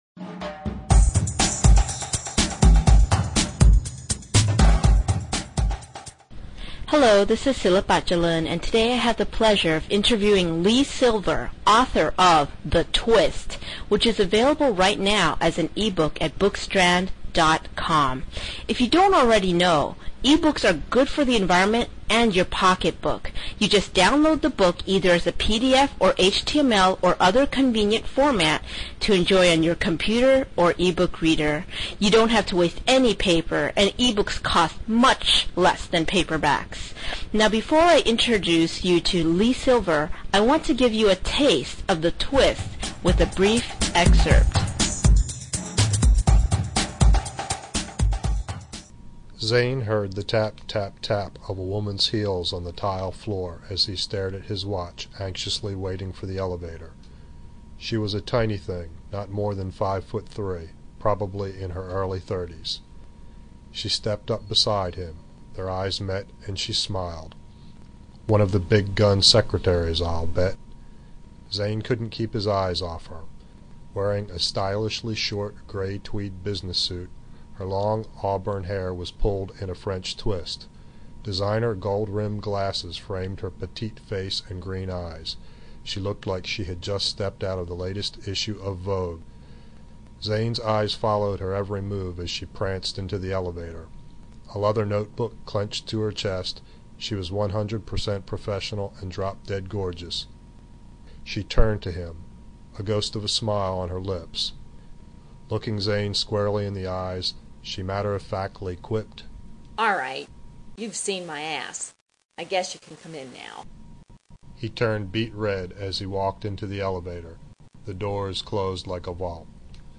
It's fast-paced & fun, weaving a blend of sound tracks and sound bites that would make Barbara Walters blush. The interview was as natural as if we were chatting in the kitchen over a cup of coffee.
interview.wav